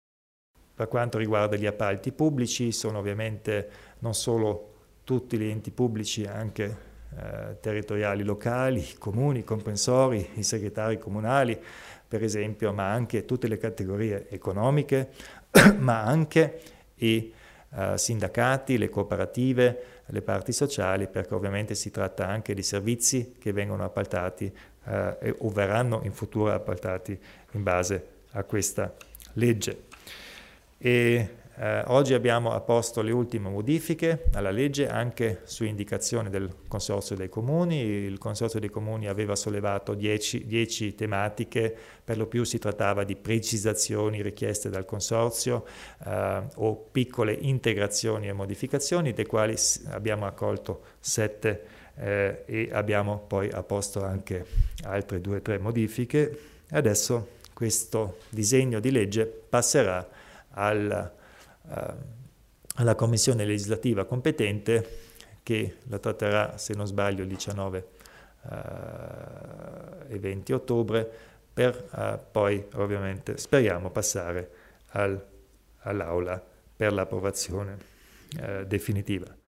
Il Presidente Kompatscher spiega il nuovo disegno di legge sugli appalti pubblici.